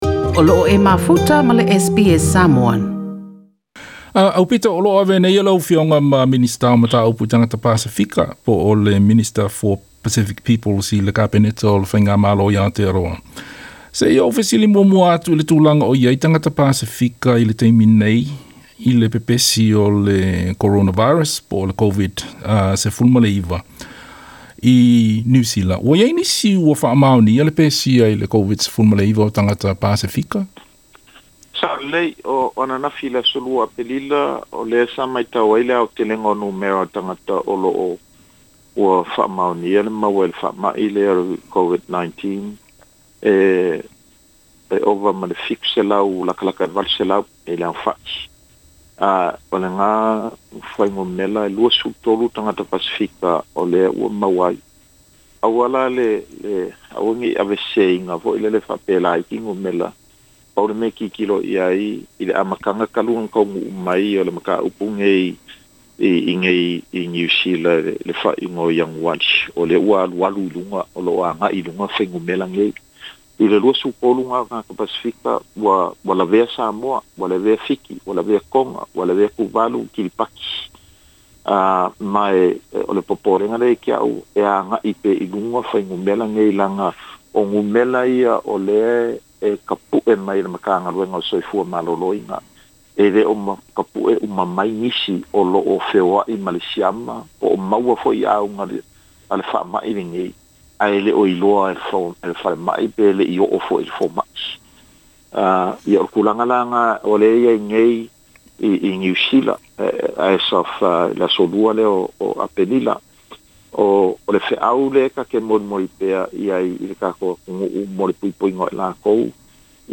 Se vaega o le TALANOA ma le Afioga Aupito William Sio, le minisita o mataupu i tagata Pasefika i le Kapeneta o le faigamalo i Aotearoa.